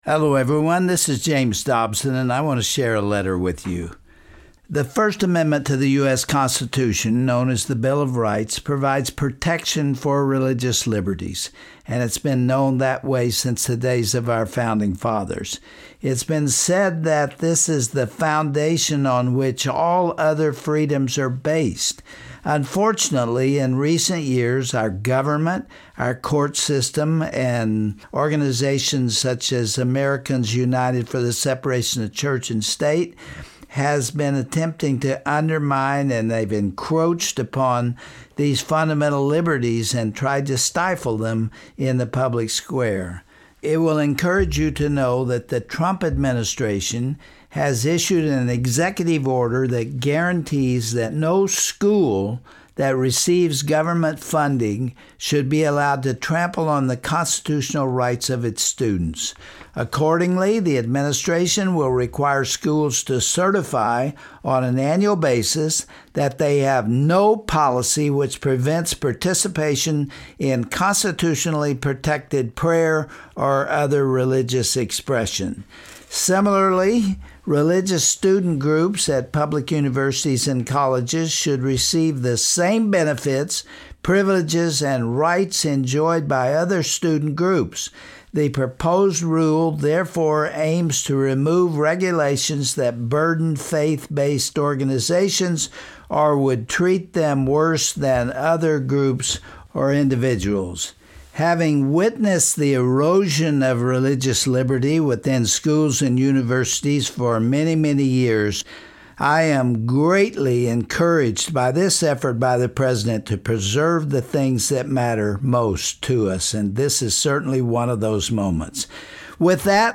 On this Family Talk broadcast, Dr. Dobson discusses a few prominent historical role models with Eric Metaxas, author of Seven Men: And the Secret of Their Greatness. The two analyze the assault on manhood, and our societys lack of God-honoring men.